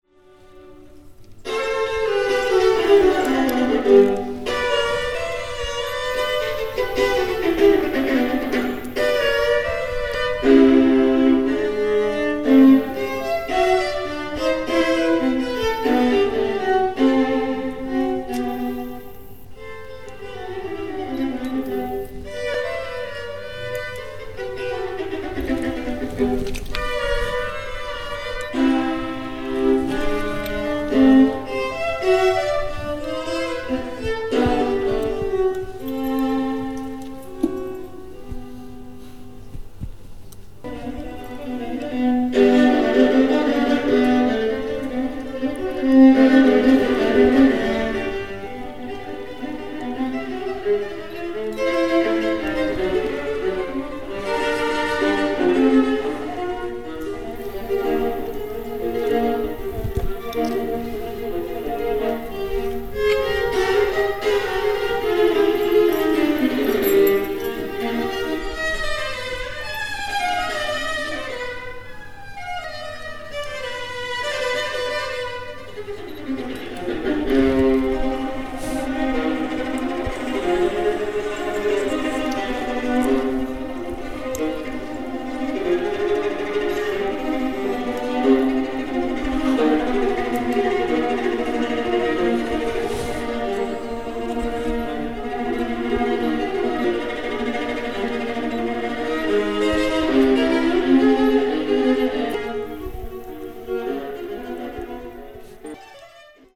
Viola, Violin